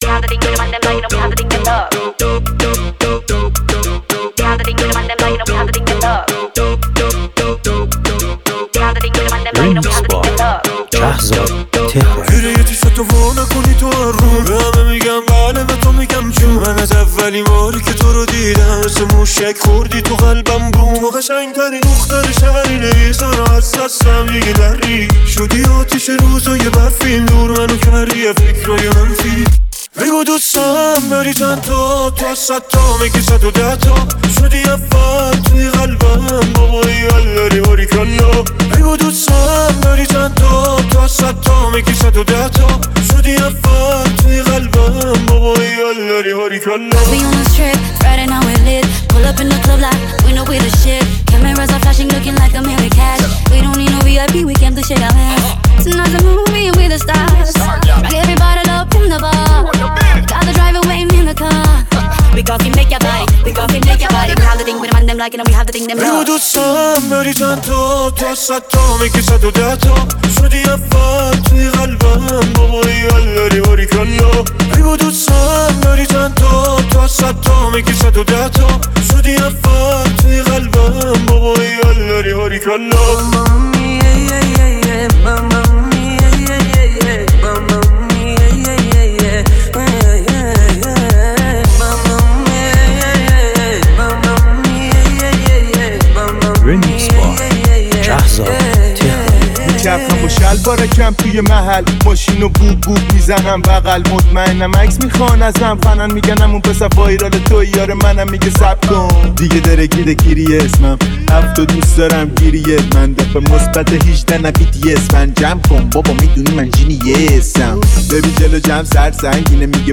این ریمیکس مخصوص مهمونی‌های پرانرژی و لحظات شاد است.